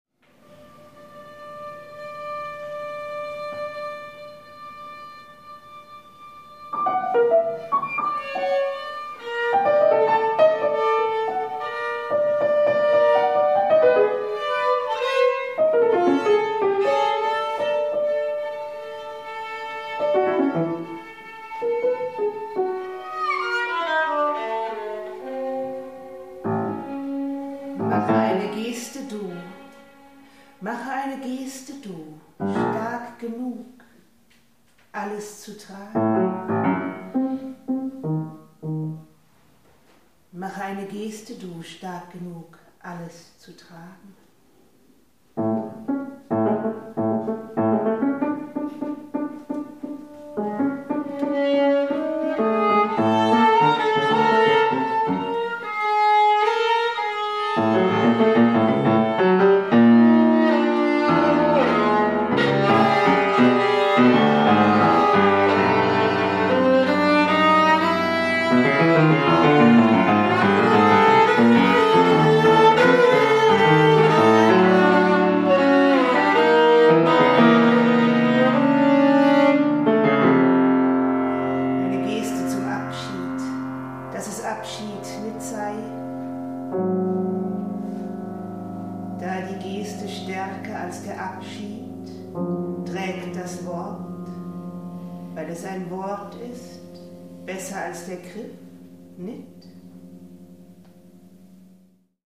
Live-Ausschnitte
Violine/Stimme
Piano/ToyPiano